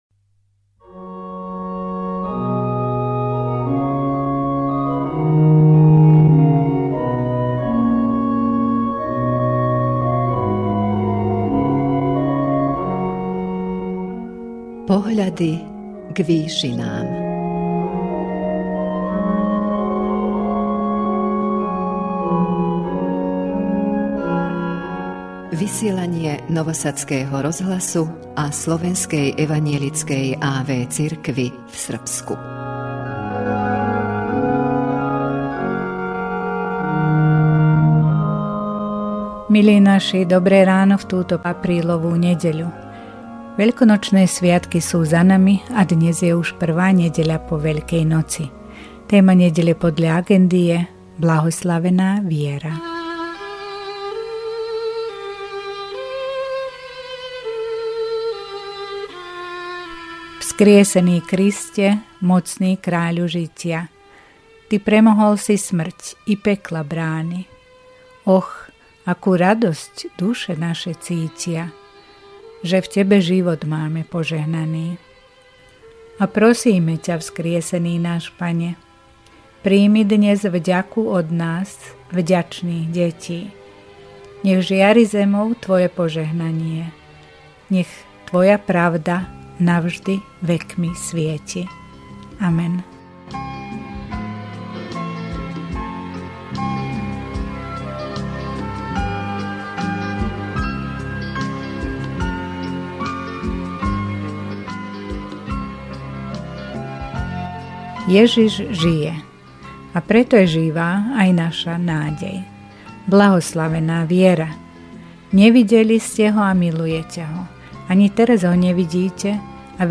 V duchovnej relácii Pohľady k výšinám Rádia Nový Sad a Slovenskej evanjelickej a.v. cirkvi v Srbsku v túto Nedeľu po Veľkej noci s duchovnou úvahou sa